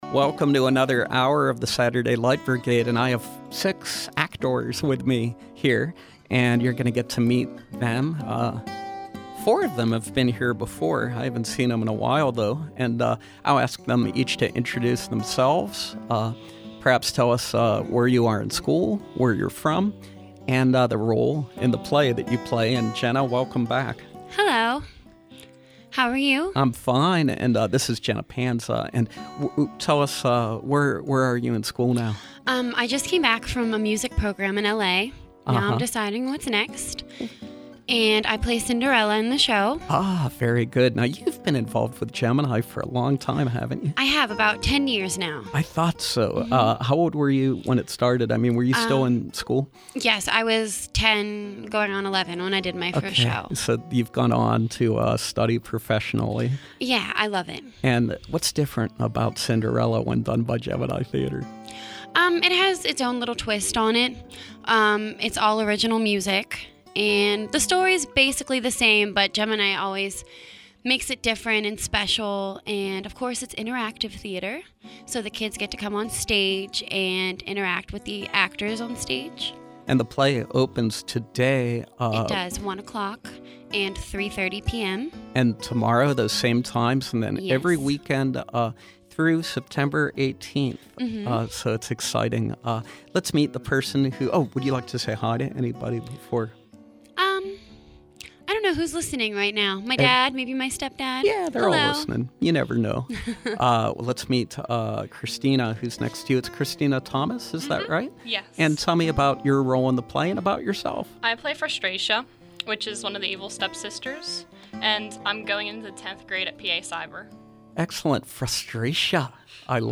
Gemini Theater produces original, interactive, children’s musicals which focus on artistic, cultural and educational themes. This week we welcome members of the cast of ‘Cinderella’ as they preview their production of this classic story.